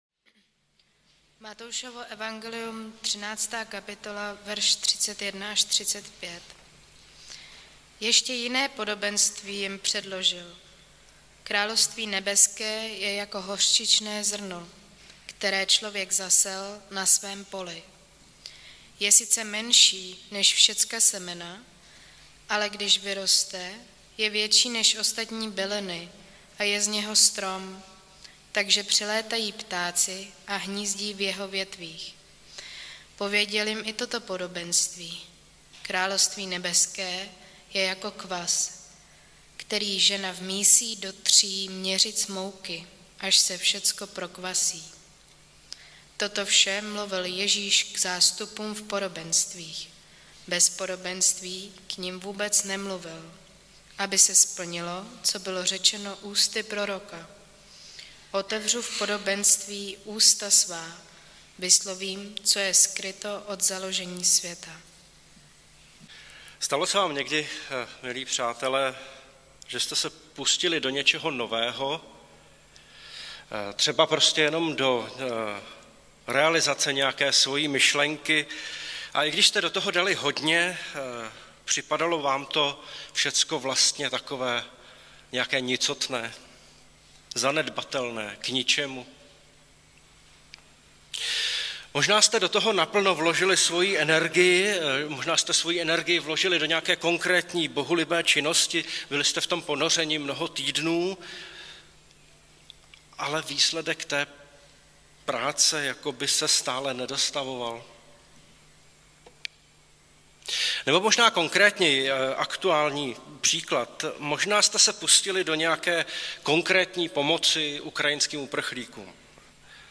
Nedělní kázání – 20.3.2022 Moc Božího království